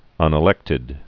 (ŭnĭ-lĕktĭd)